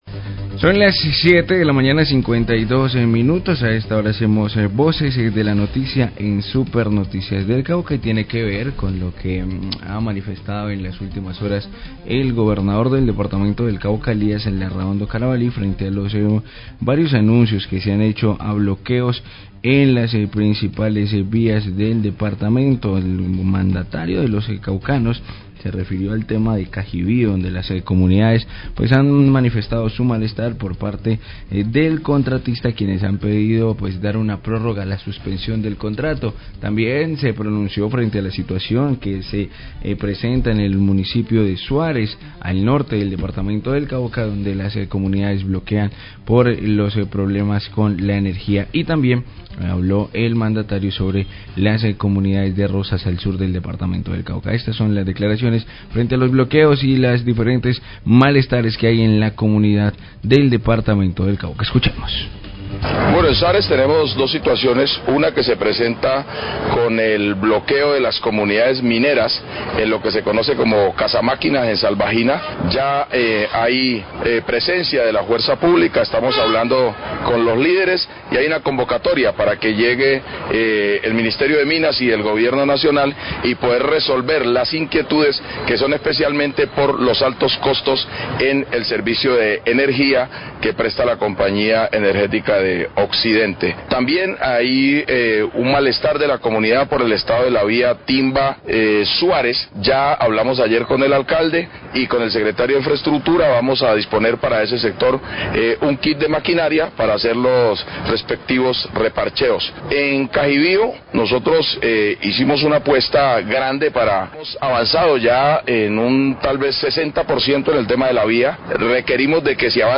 Radio
El Gobernador del Cauca, Elias Larrahondo Carabalí, se pronuncia frente a los dos hechos de orden público como los bloqueos de vías en Suárez y Cajibio. También se refirió a la toma de casa máquinas de La Salvajina y a las deficiencias del servicio de energía, como causa de las protestas.